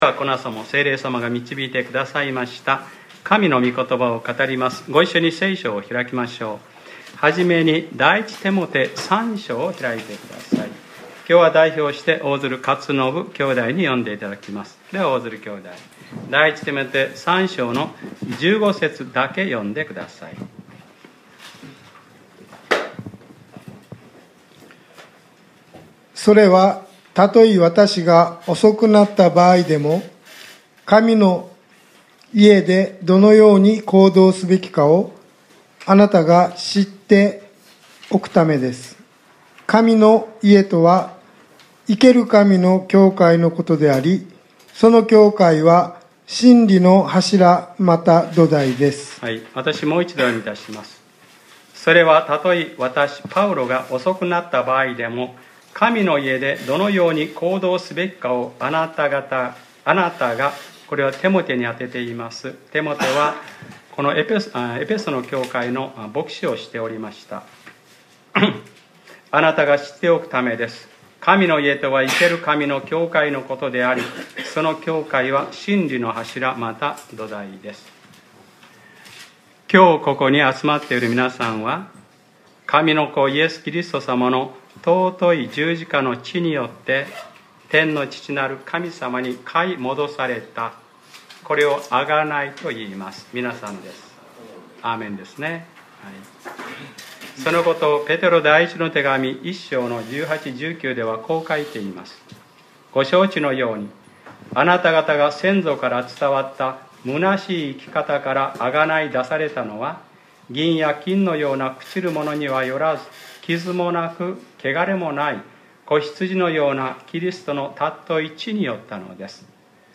2018年07月22日（日）礼拝説教『神の家でどのように行動すべきか』